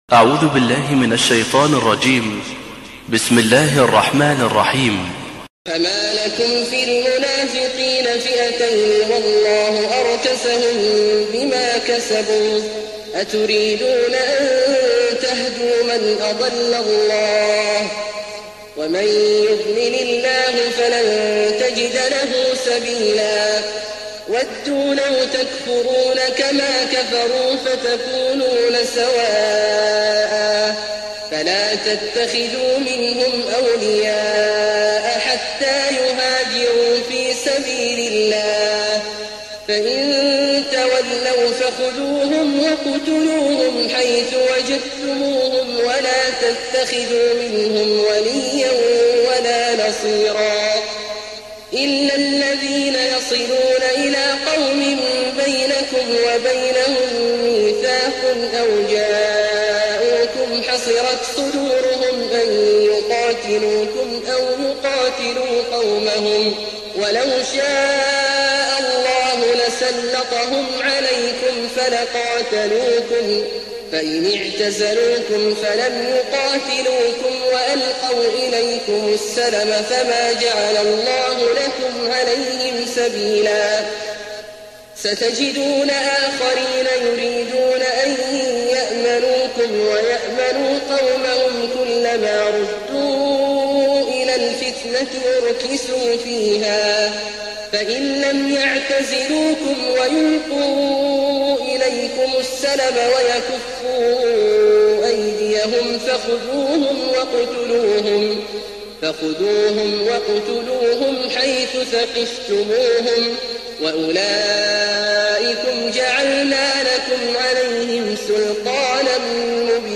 تهجد ليلة 24 رمضان 1419هـ من سورة النساء (88-147) Tahajjud 24th night Ramadan 1419H from Surah An-Nisaa > تراويح الحرم النبوي عام 1419 🕌 > التراويح - تلاوات الحرمين